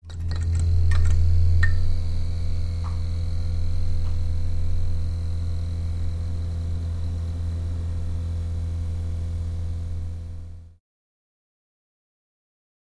Звуки неона
Погрузитесь в атмосферу ночного города с подборкой звуков неона: мерцание вывесок, тихий гул ламп, электронные переливы.